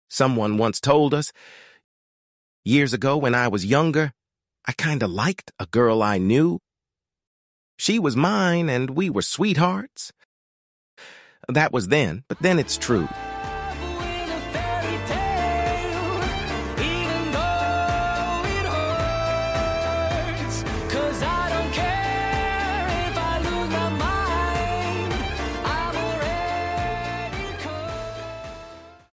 cheerful and catchy song